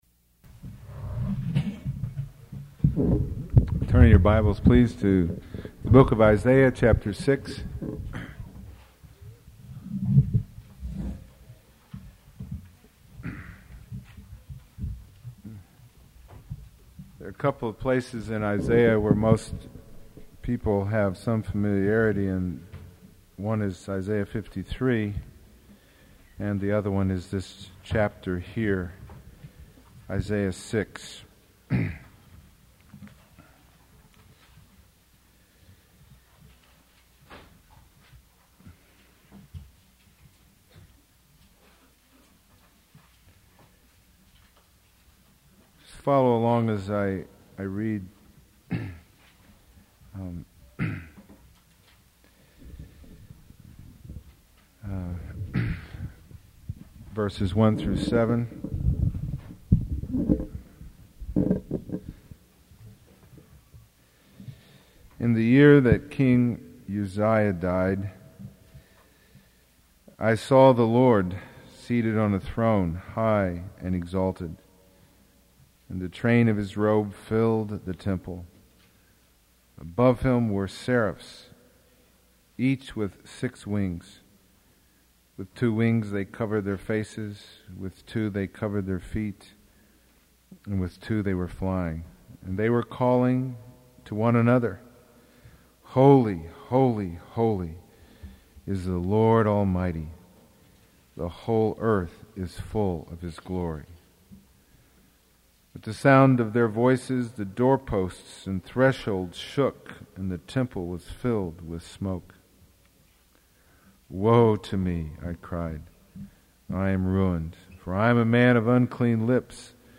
Sermon 39